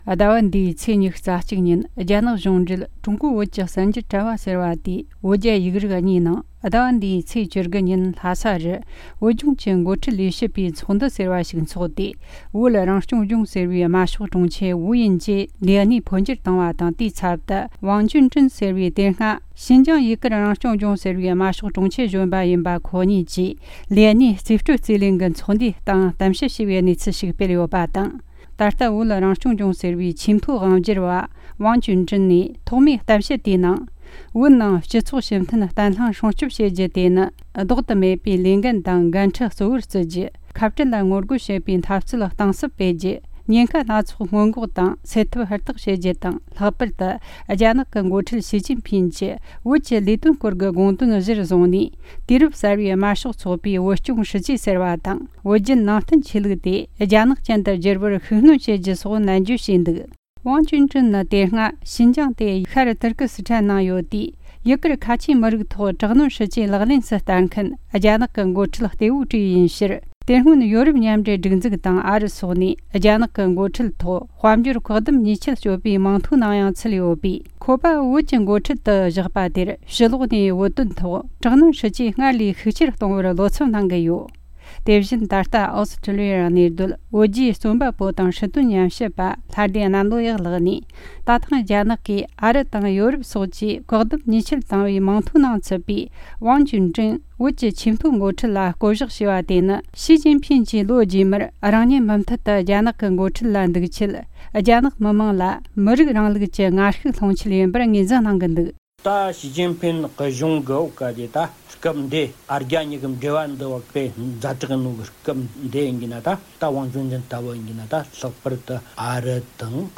བཅར་འདྲི་དང་གནས་ཚུལ་དབྱེ་ཞིབ་རྒྱང་སྲིང་བྱས་བར་གསན་རོགས་གནོངས།